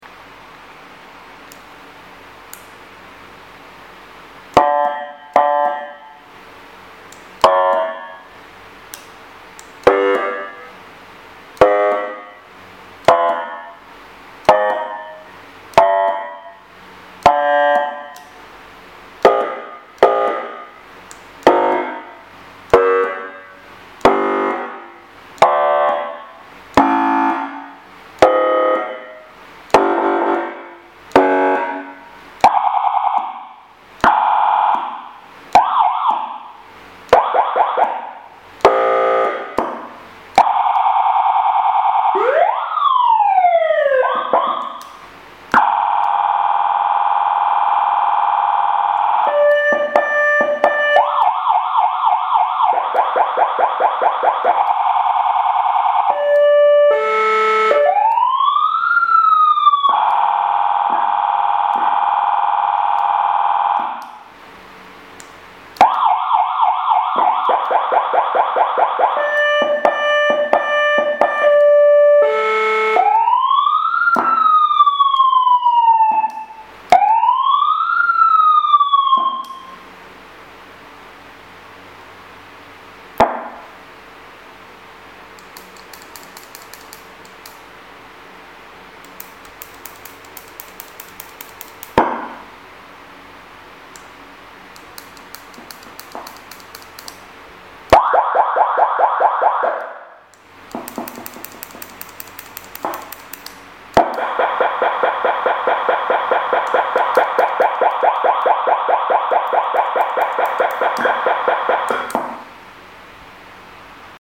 Siren Sound wired Controller Demo sound effects free download
Ambulance Police Firetruck EMS